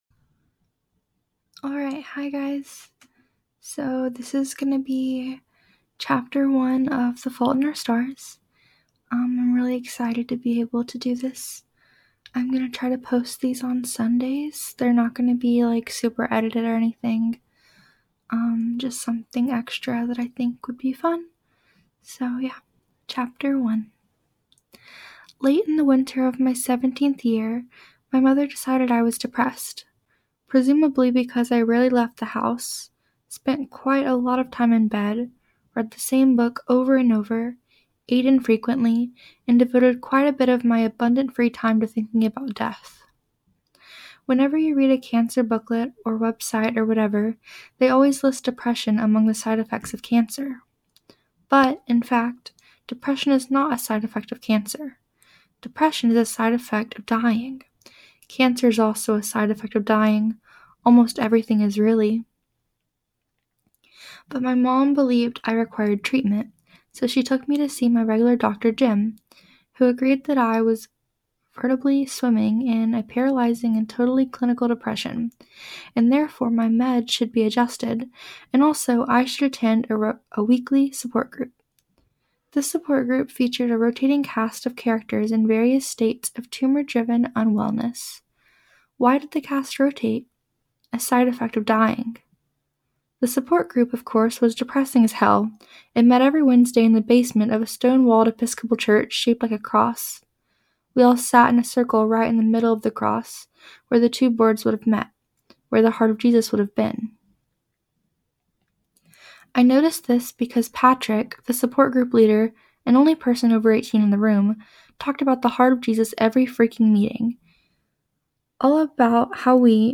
lets see how this goes. here is chapter 1... sorry i sound a bit shaky and stuff in some of it, i feel pretty anxious tonight and i think it was reflected a bit in my voice. i tried to sound as normal as possible. let me know how i can improve next week's upload.